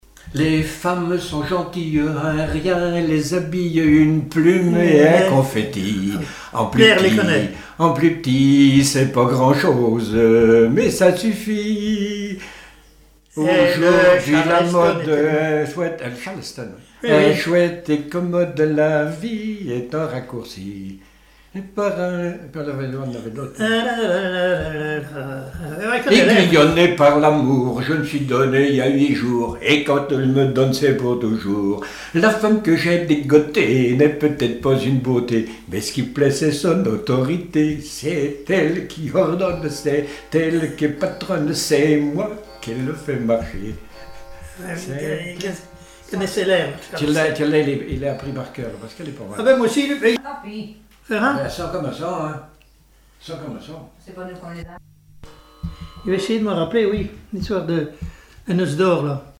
Chants brefs - A danser
danse : charleston
Pièce musicale inédite